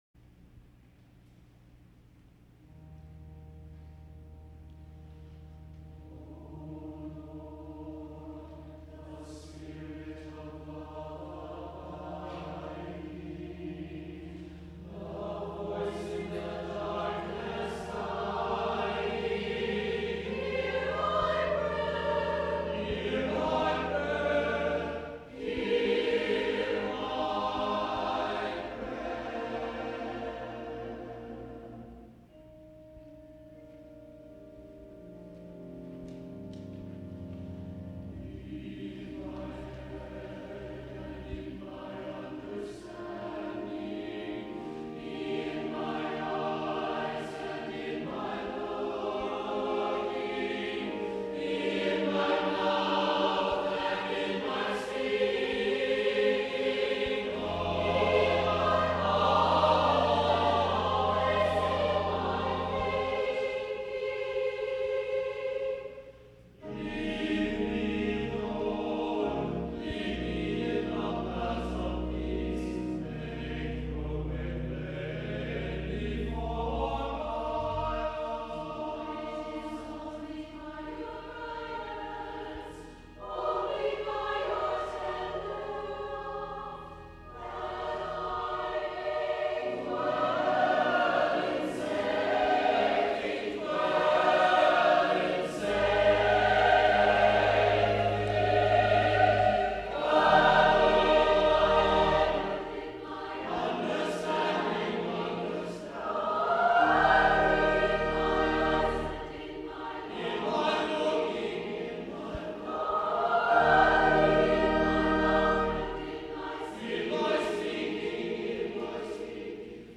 for SATB Choir and Organ or Piano (2004)